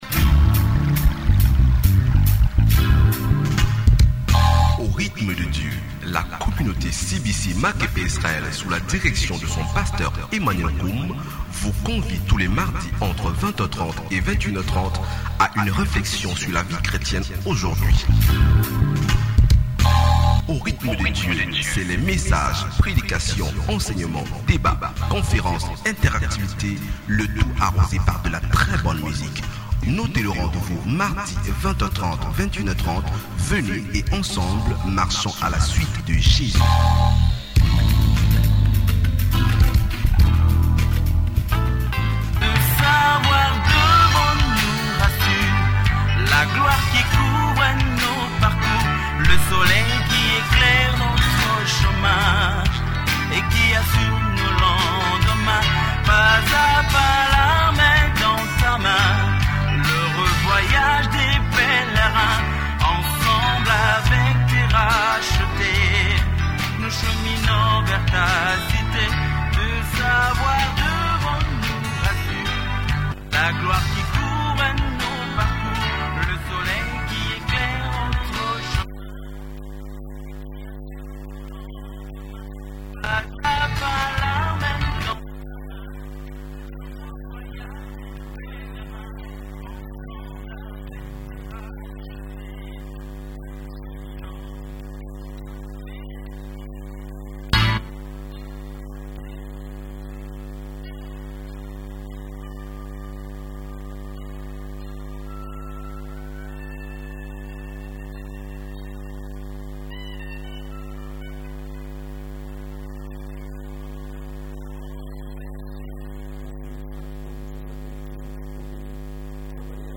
Evangelist